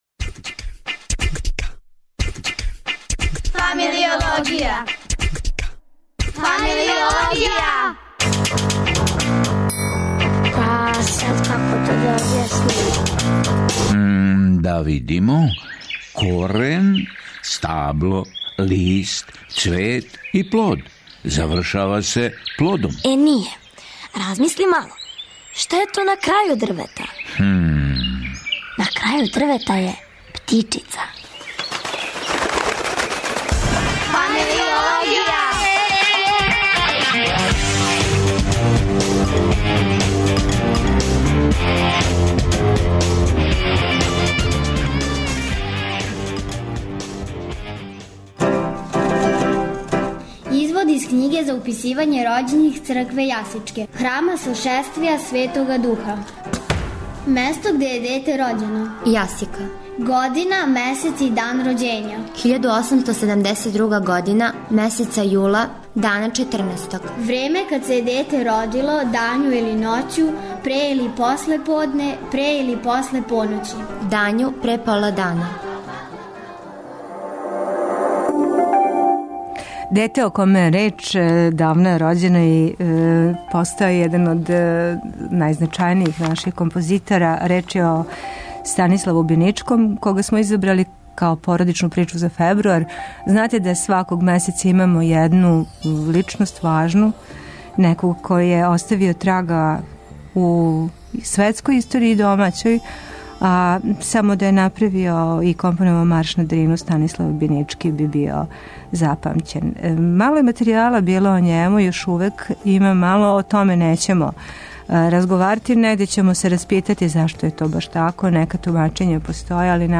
Пре подне је, треба да почне отворена проба симфонијског оркестра са изузетним младим уметником.